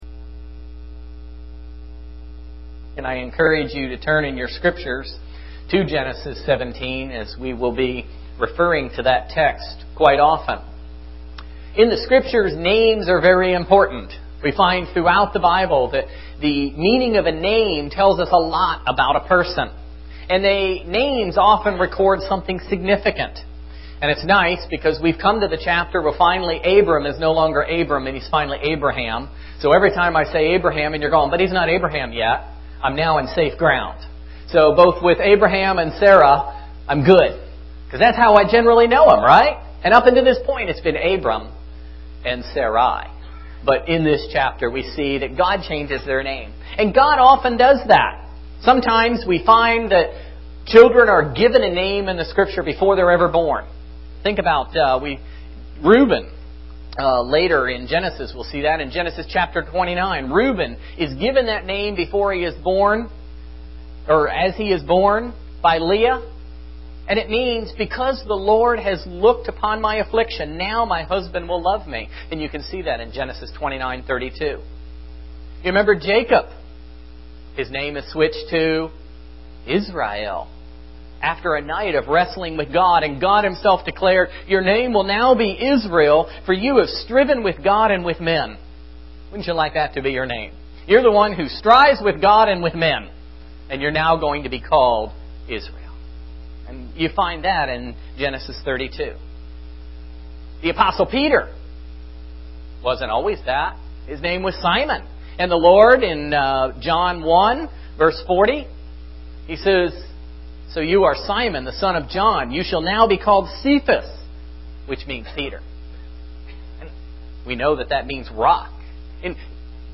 Genesis 45:16-46:27 Service Type: Sunday Morning Worship